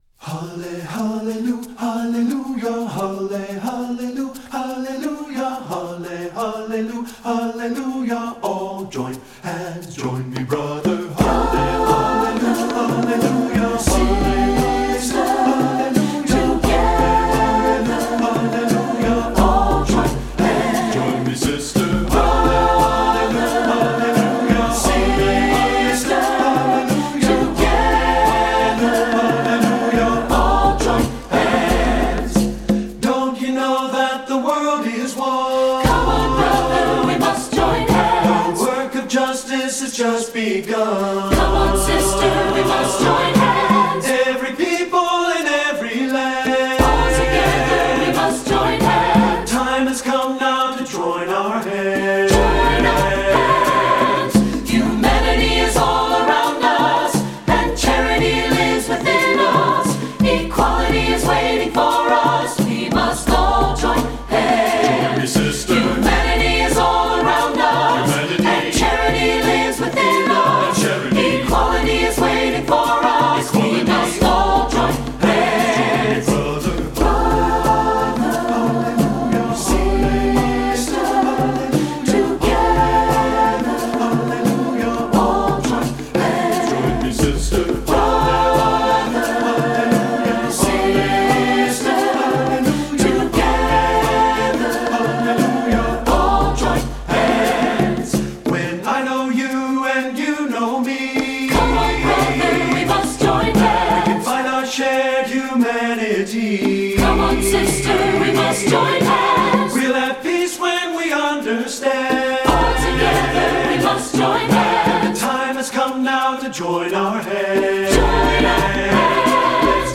secular choral
- SATB a cappella, sample